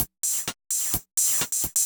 Index of /musicradar/ultimate-hihat-samples/128bpm
UHH_ElectroHatC_128-04.wav